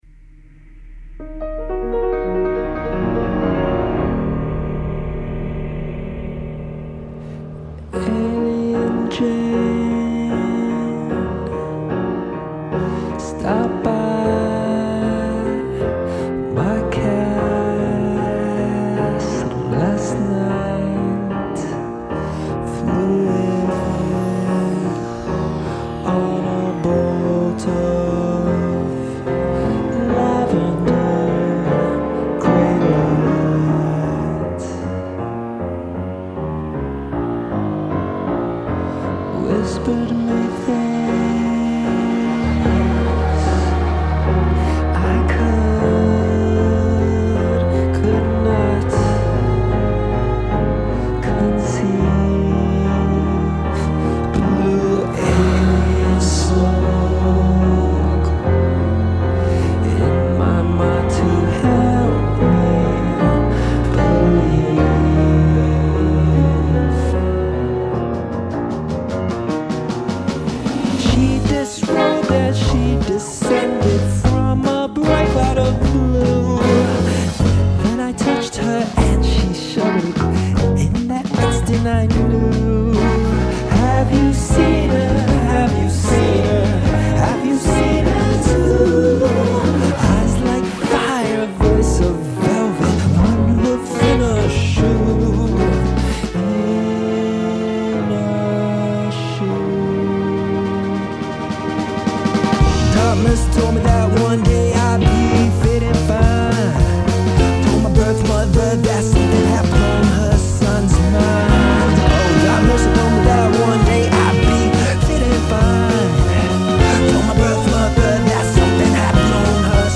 click FIRE BELOW to hear the haunting song of a young victim who has been visited by one of the Vampire's "FAMILIARS"...
MUSIC COMPOSED